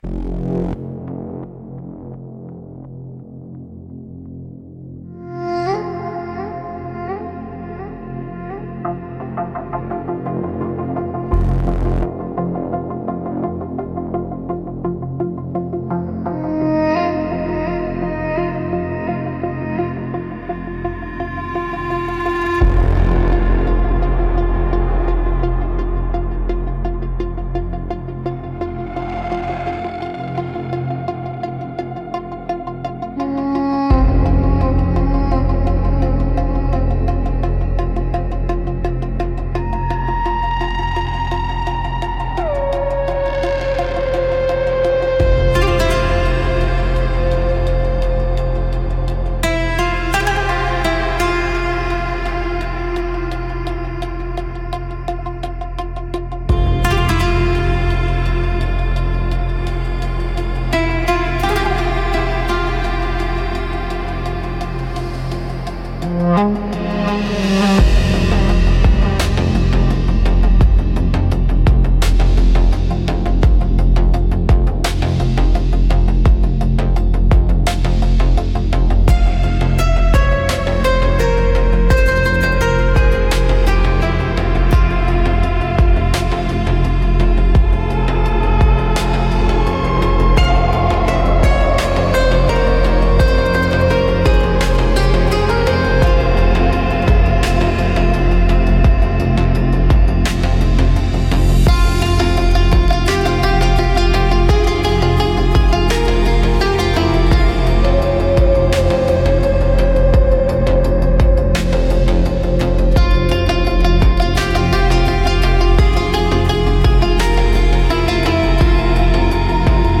Instrumentals - Memory Leak Lullaby